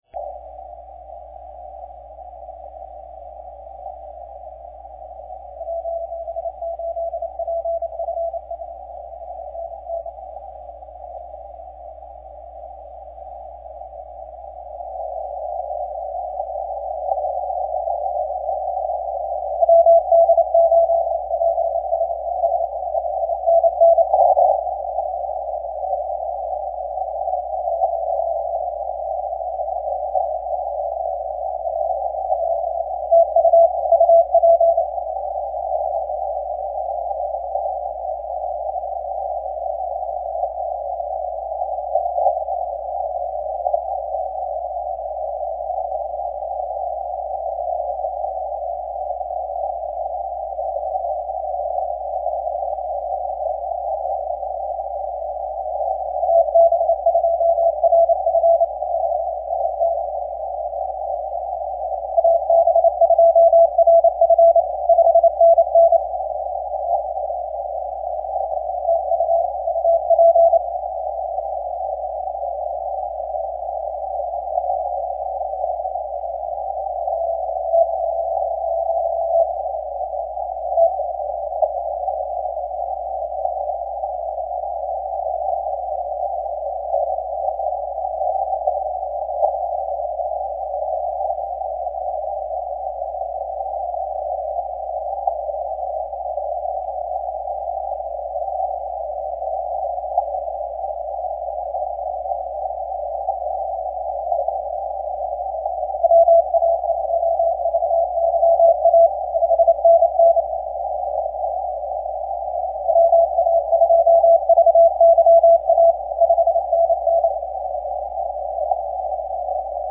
I started another recording 30 minutes after their sunrise (0540Z) and 3Y0K is much, MUCH stronger.